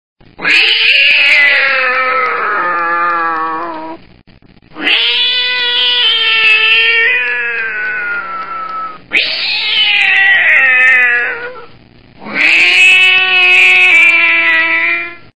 Vopli_kota.mp3